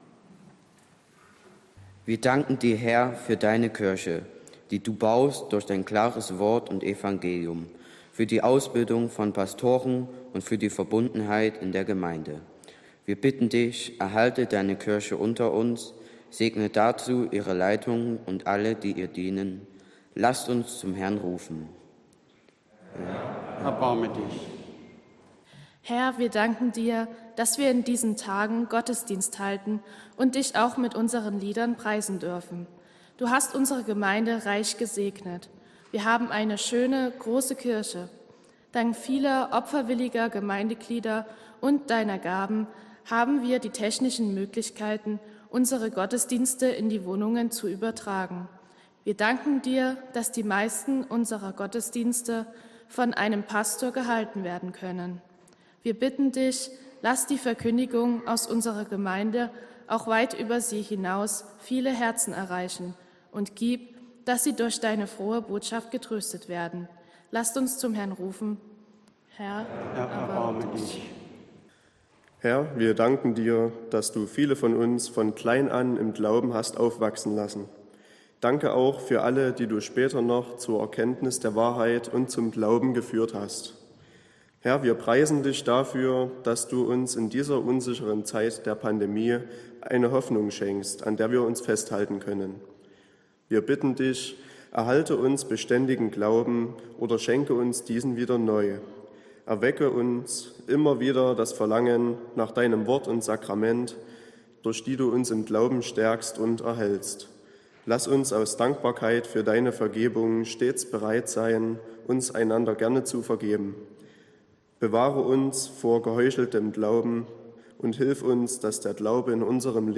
Gottesdienst am 12.12.2021
Diesen Gottesdienst hat die Jugend unserer Gemeinde maßgeblich mit vorbereitet und ausgestaltet.